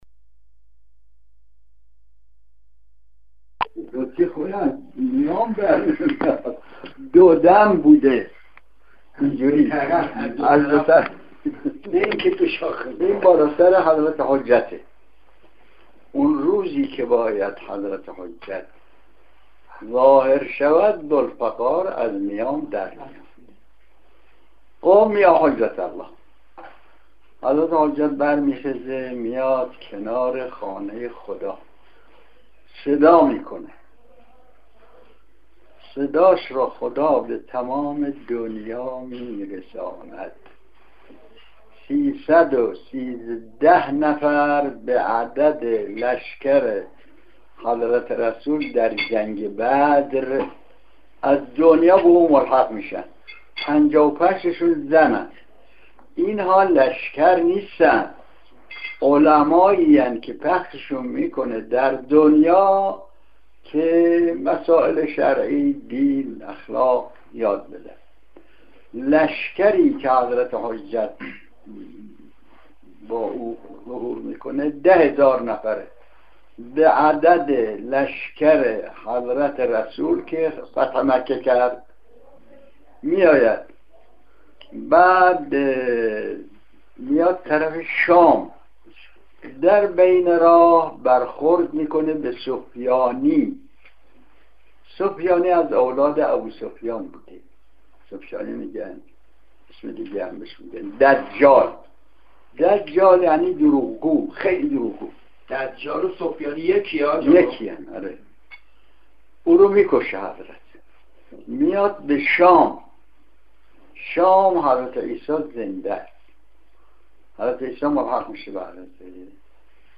سخنرانی علامه عسکری (رحمت الله علیه)
در دیداری که درسالروز ولادت نبی مکرم اسلام وامام صادق علیهماالسلام بامعظم له درآخرین سالهای عمرشریفشان صورت گرفت بعد از سخنرانی مرحوم علامه استادارجمند دقایقی مداحی کردند که فابل صوتی جالب این دیدارجهت استفاده در ذیل بارگزاری گردید .